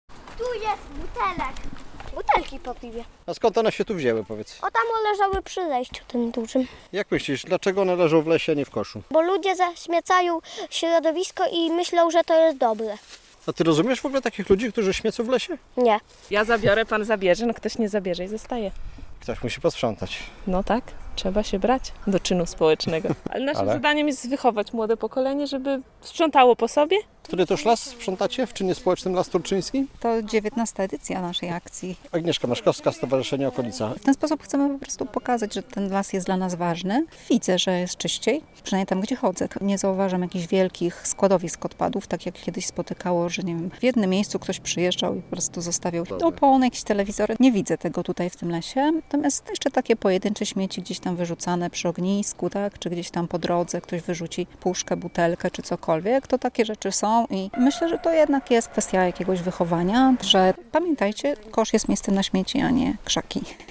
Akcja sprzątania Lasu Turczyńskiego - relacja